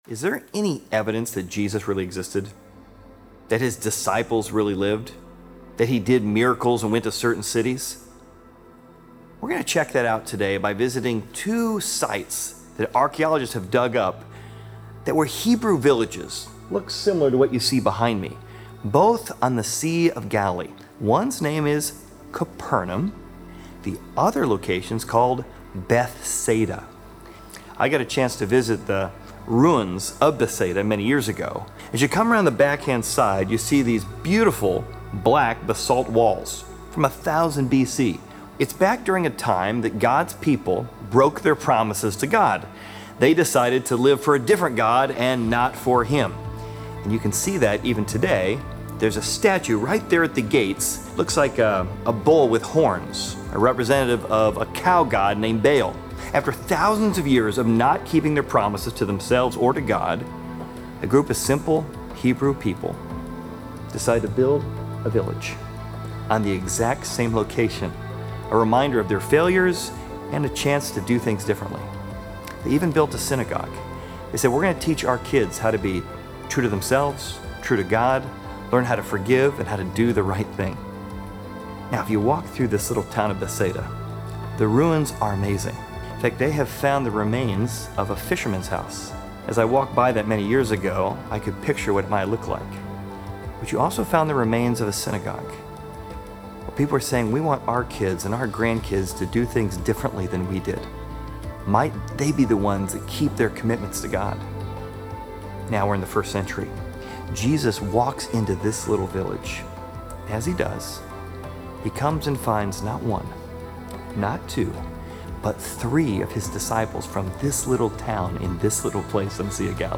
Exploring Service / Raiders of The Lost Cities / Bethsaida